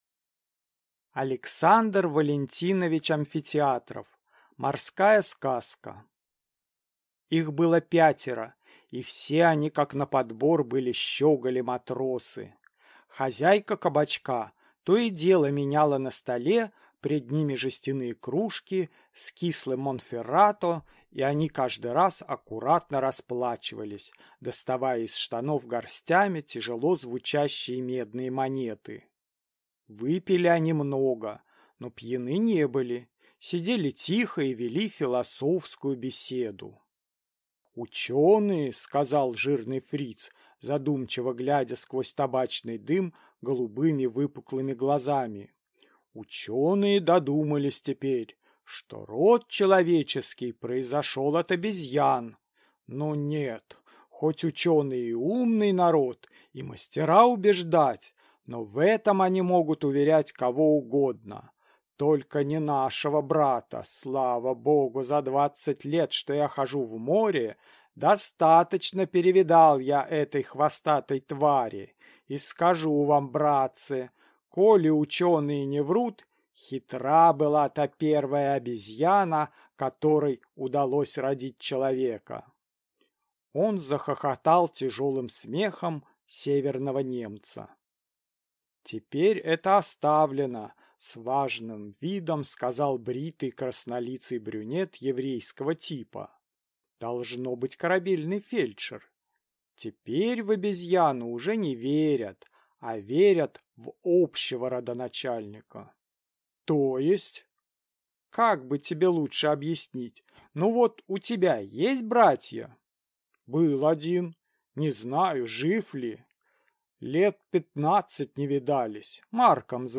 Аудиокнига Морская сказка | Библиотека аудиокниг